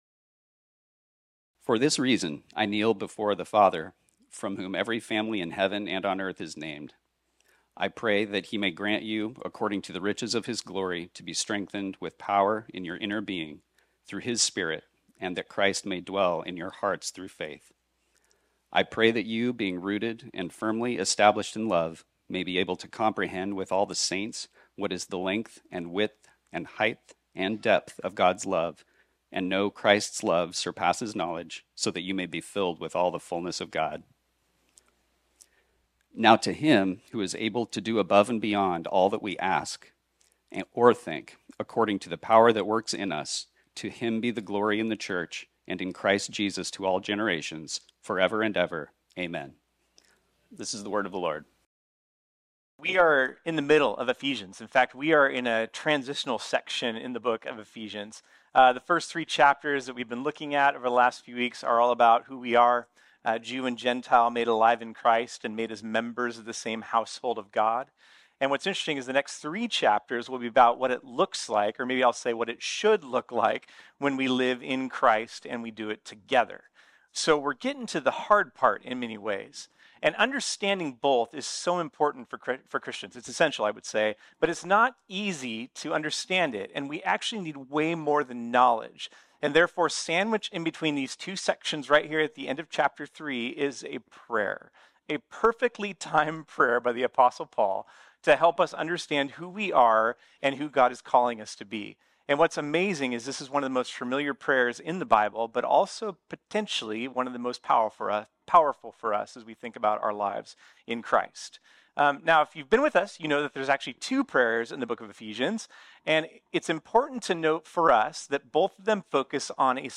This sermon was originally preached on Sunday, October 15, 2023.